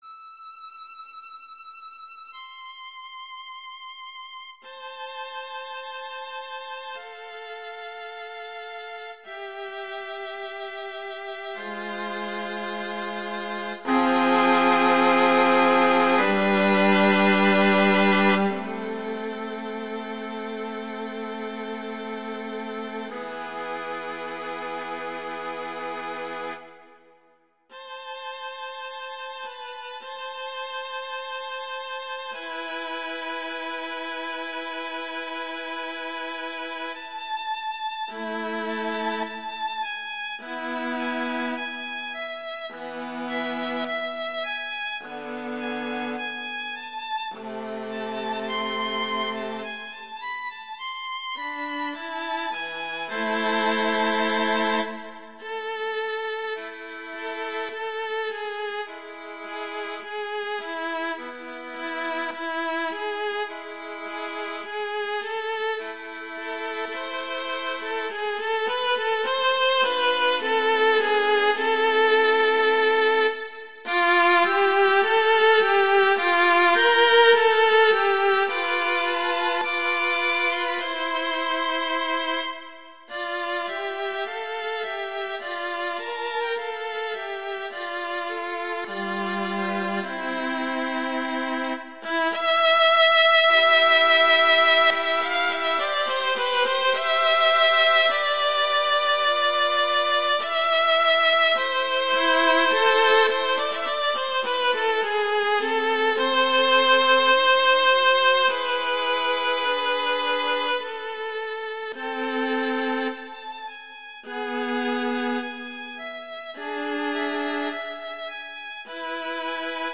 classical
A minor
♩=52 BPM